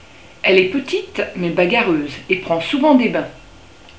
La mésange bleue